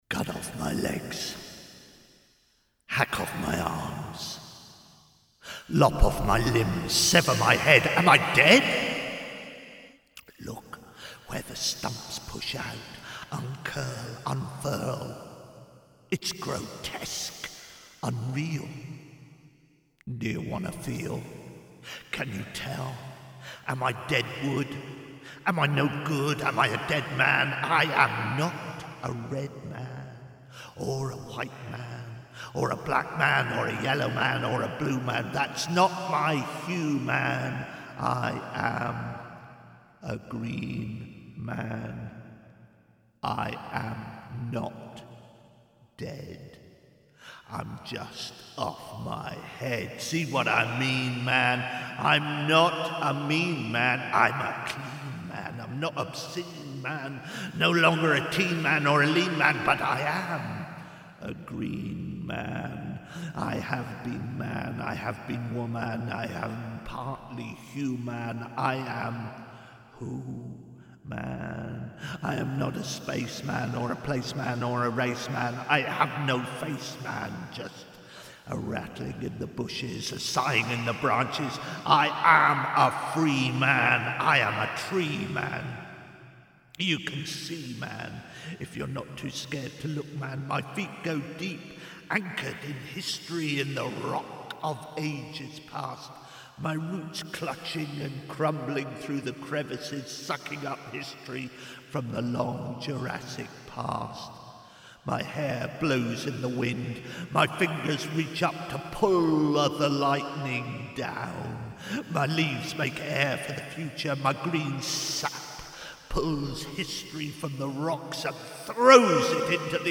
Well, not live this time but still kicking with the glee of a happy pregnancy.